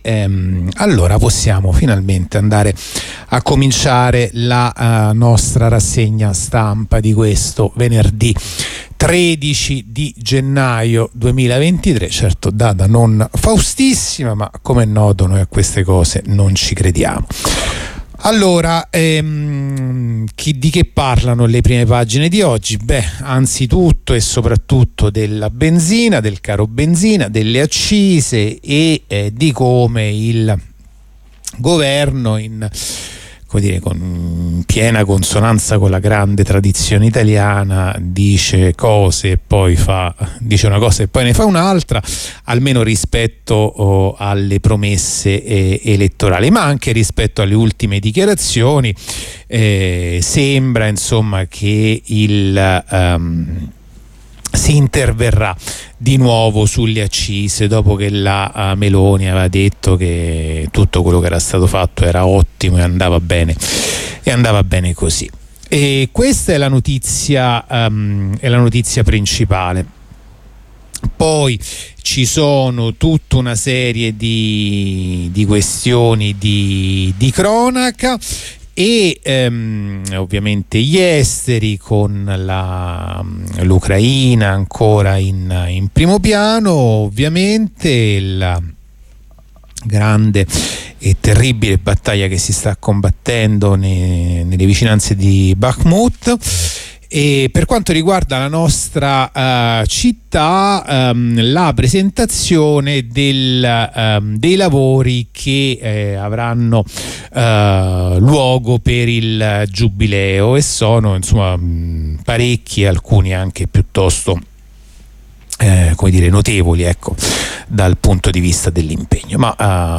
La rassegna stampa di venerdì 13 gennaio 2023
La rassegna stampa di radio onda rossa andata in onda venerdì 13 gennaio 2023